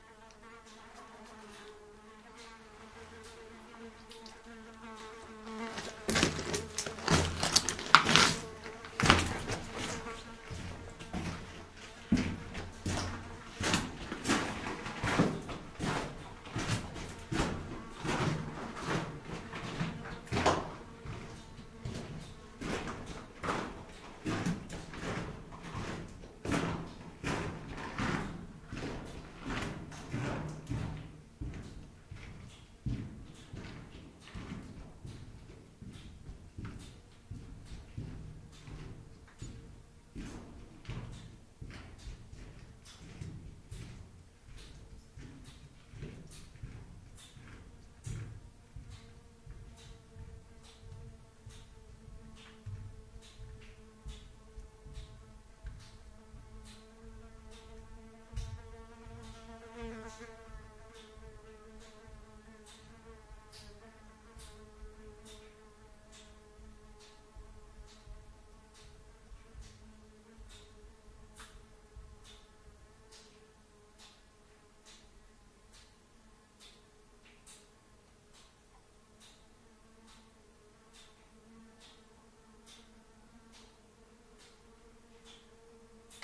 森林中的人们 " 在多风的森林中接近 (2人)
描述：现场记录了两根竹竿接近（三次）话筒的过程。脚步声和树枝破裂声。
Tag: 森林 步骤 树林 行走 脚步